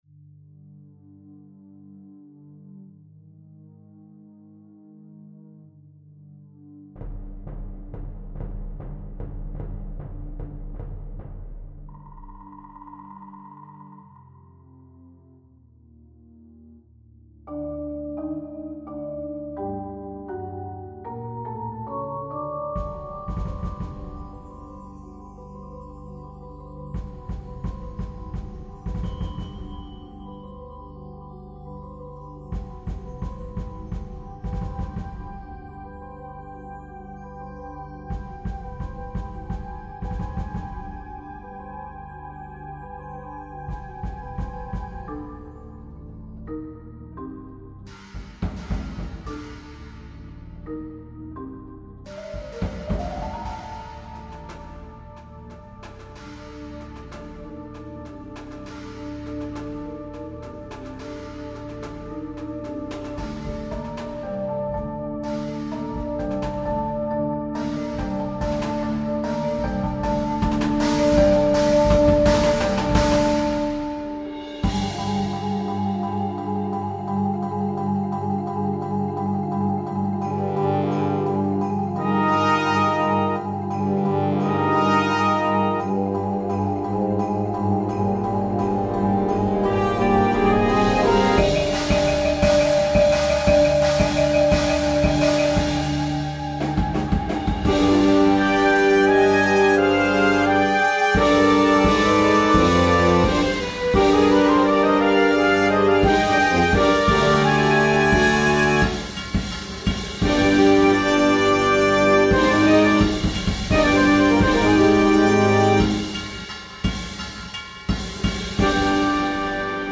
for an exciting presentation